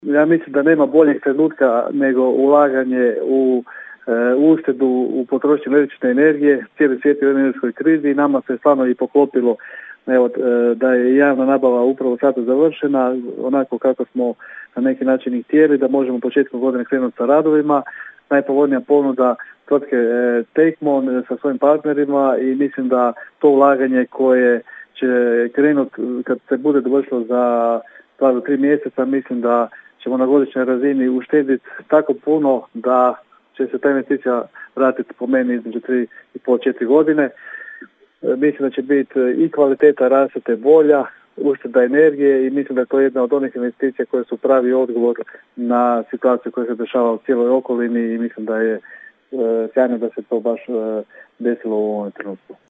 Govori labinski gradonačlenik Valter Glavičić: (
ton – Valter Glavičić).